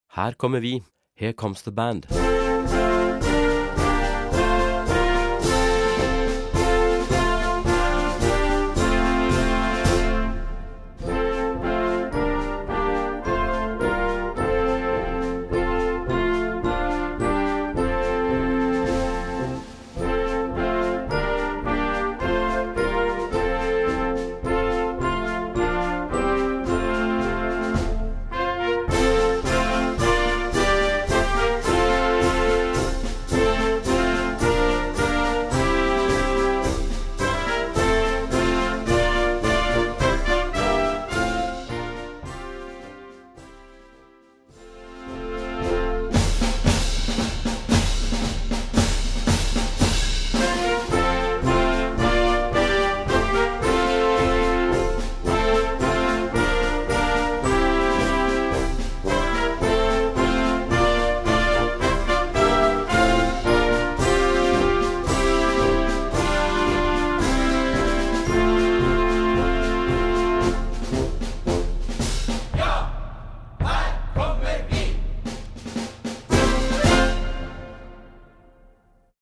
Besetzung: Blasorchester
Part 1/melody: Flute, Oboe, Clarinet 1, Tenor sax, Trumpet 1
Part 4/bass: Bassoon, Bass clarinet, Baritone sax, Tuba